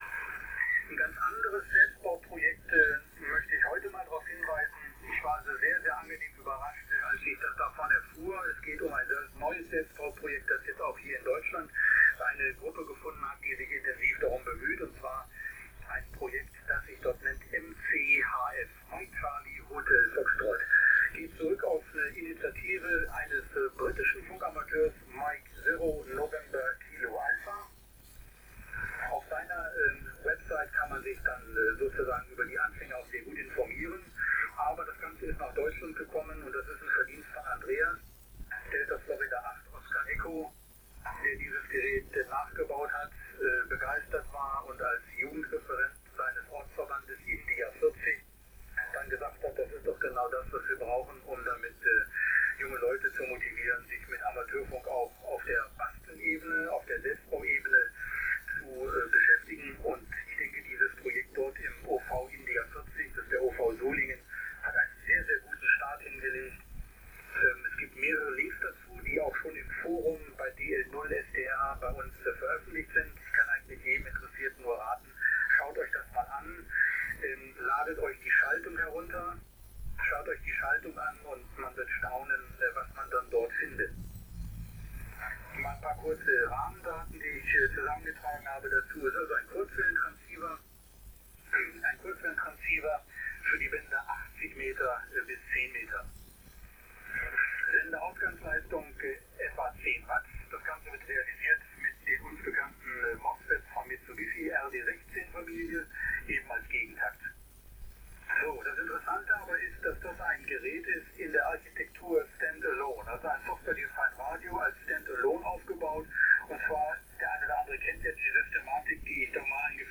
Hier der Ausschnitt über den mcHF als Mikrofonmitschnitt von meinem mcHF zum Download.